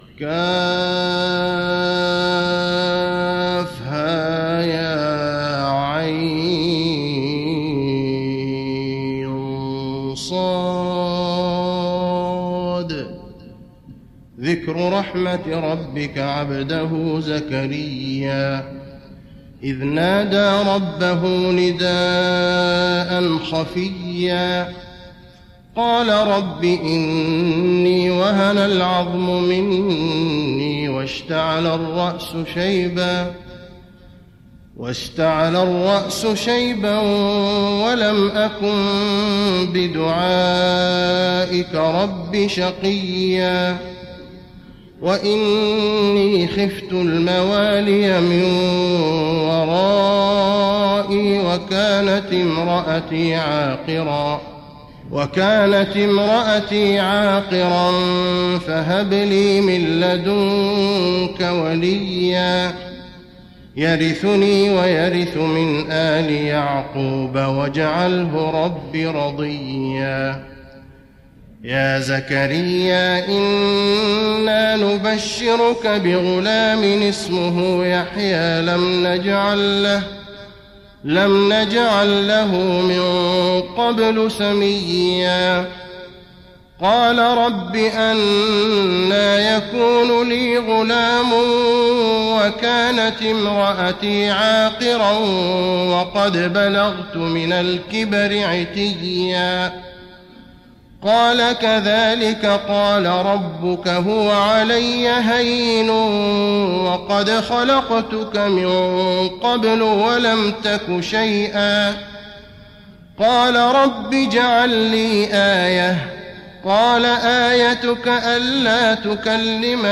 تراويح رمضان 1415هـ من سورة مريم كاملة وطه (1-54) Taraweeh Ramadan 1415H from Surah Maryam and Taa-Haa > تراويح الحرم النبوي عام 1415 🕌 > التراويح - تلاوات الحرمين